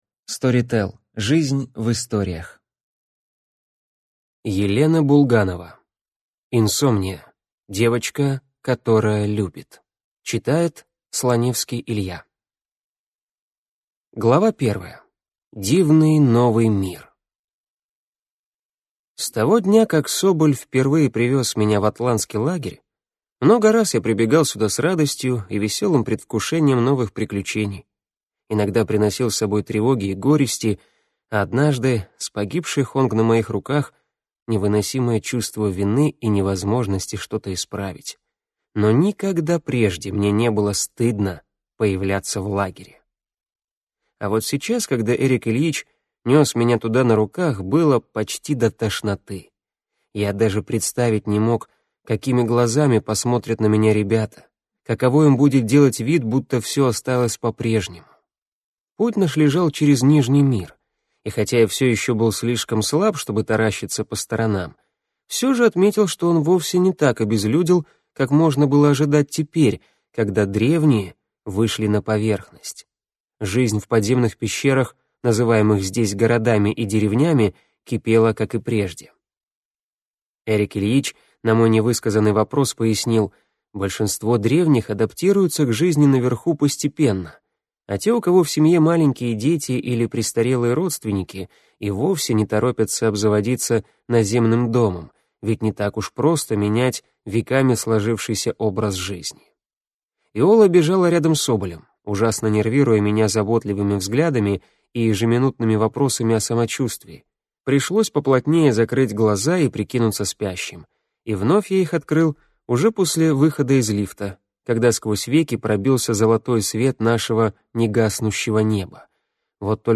Аудиокнига Девочка, которая любит | Библиотека аудиокниг
Прослушать и бесплатно скачать фрагмент аудиокниги